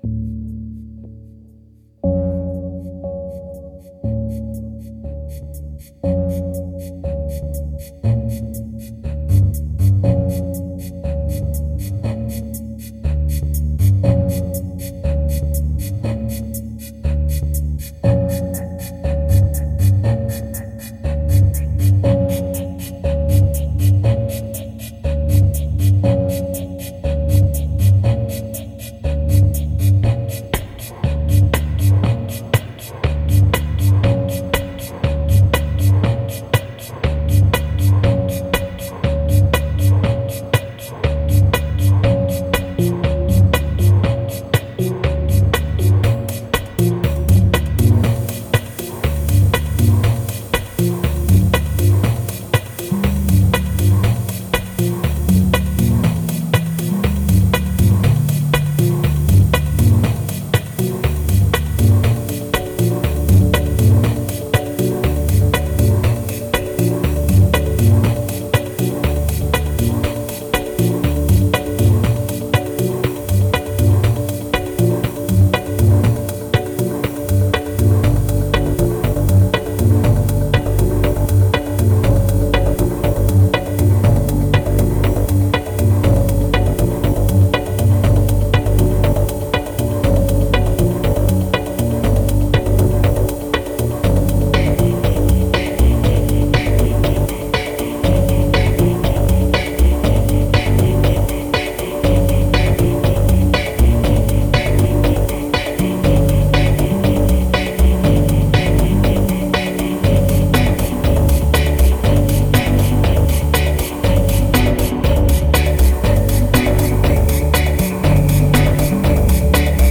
1668📈 - 6%🤔 - 120BPM🔊 - 2014-05-08📅 - -157🌟